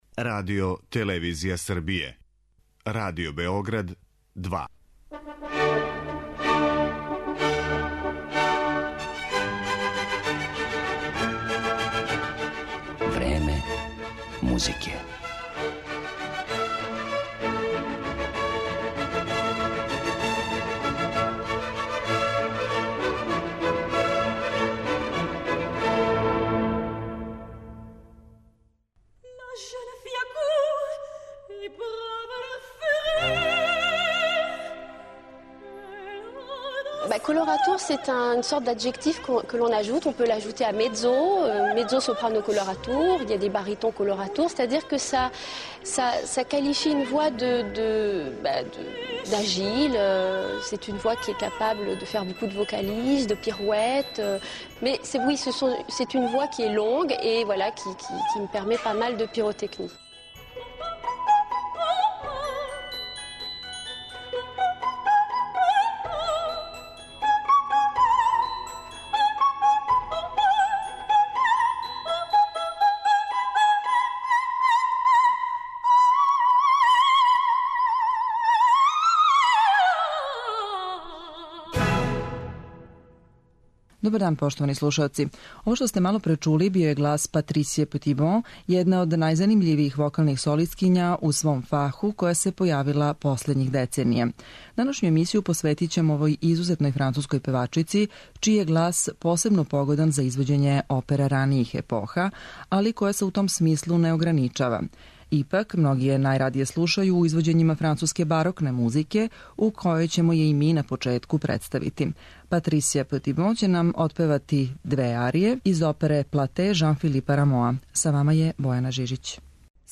колоратурном сопрану